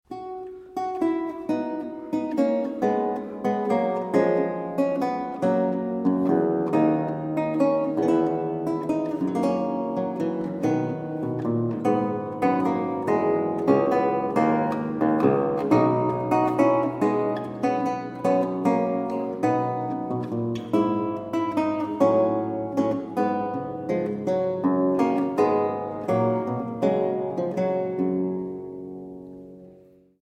Italská kytarová a theorbová hudba ze 17. století
Kaple Pozdvižení svatého Kříže, Nižbor 2014
Tiorba sola